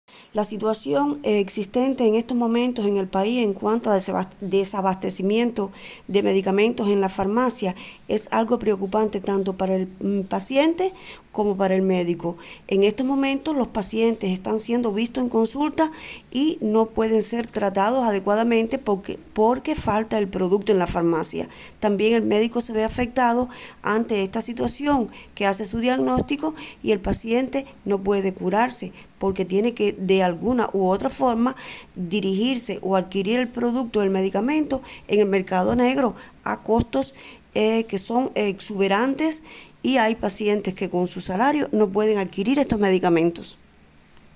Entrevista-doctora-farmacos.mp3